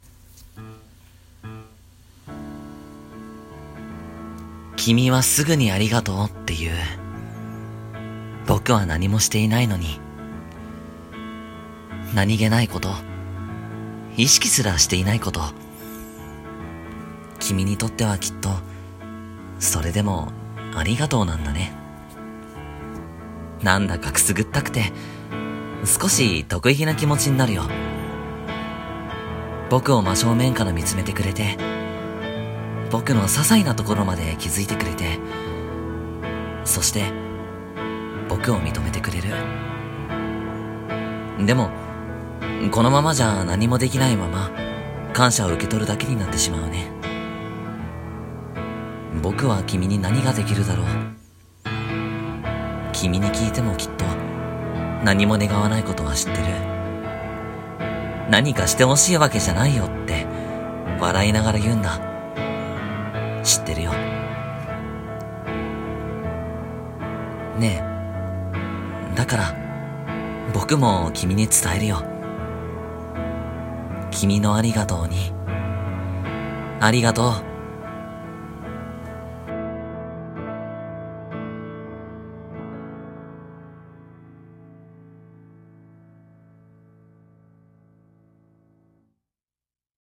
声劇 朗読】君へ、ありがとう